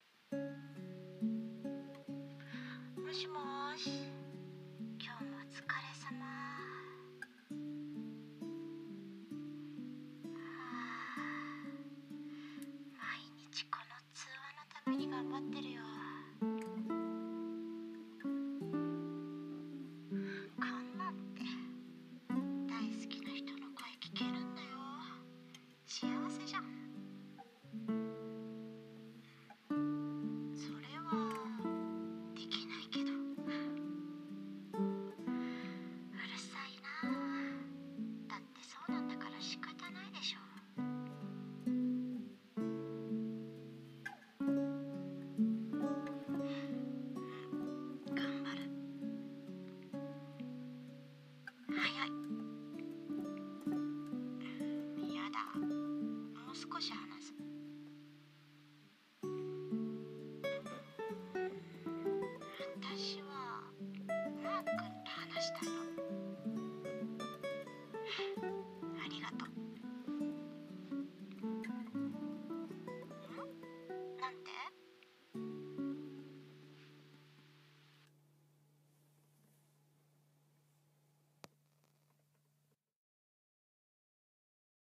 【声劇台本】寝る前に聴く、君の声【掛け合い】